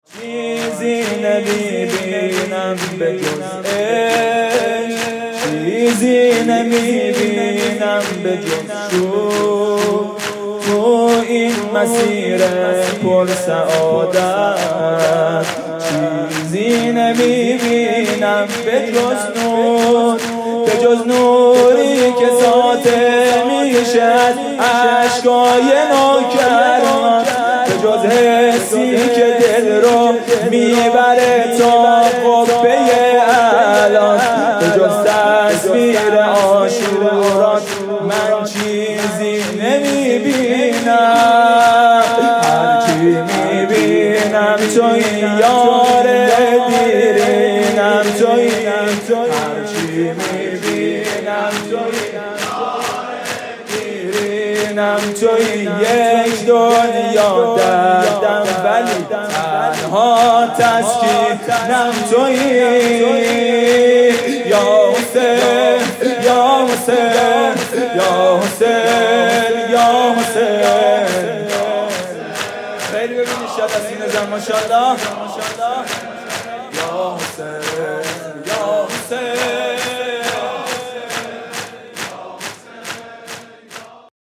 شب پنجم فاطمیه اول ۱۴۰۴ | هیأت میثاق با شهدا
music-icon واحد